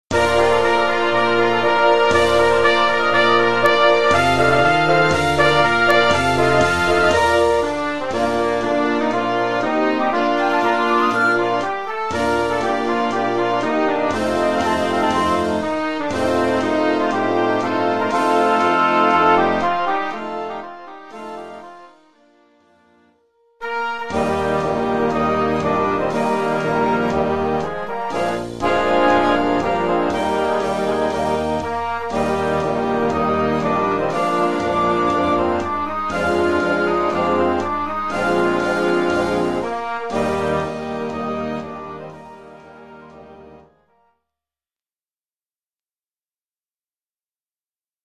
Collection : Harmonie (Marches)
Marche-parade pour harmonie-fanfare,
avec tambours et clairons ad lib.